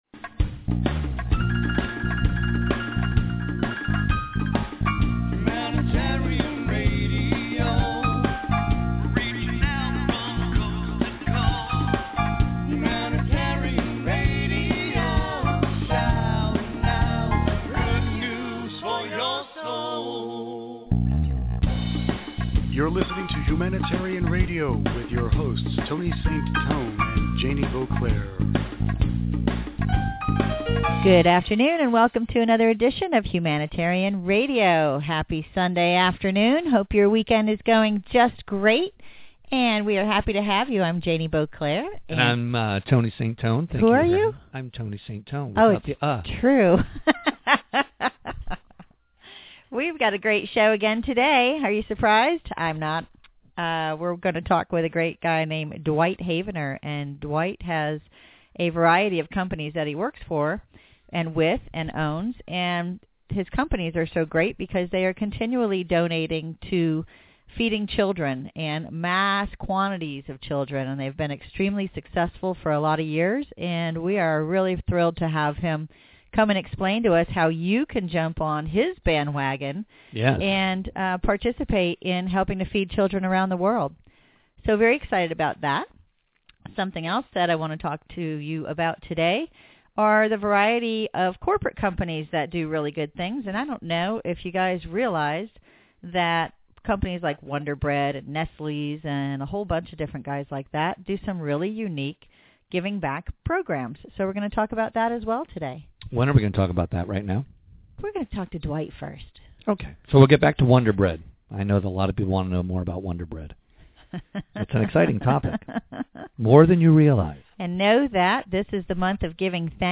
Self Help Humanitarian Radio interview